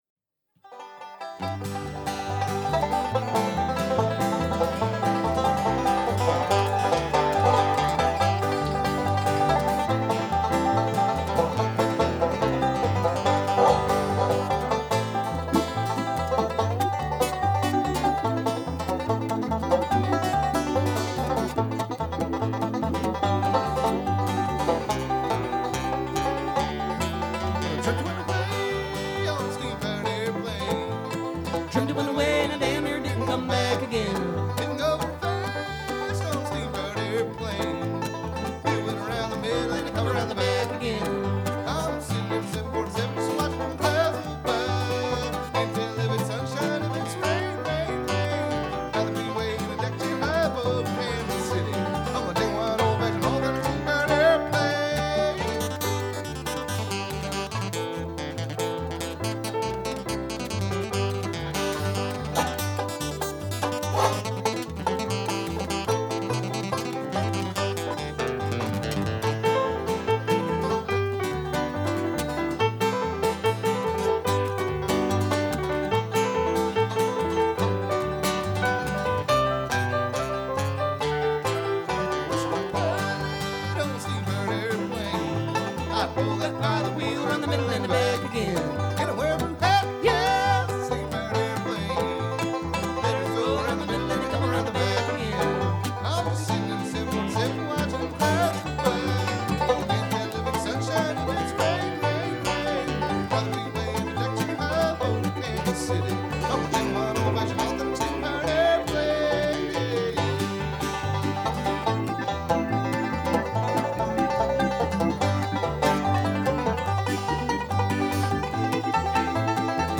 Alligator Alley Oakland Park, Florida
Banjo,Fiddle,Mandolin,Electric Banjo, Vocals
Organ, Clavinet, Piano, Bass, Melodica, Vocals
Guitar,Mandolin,Vocals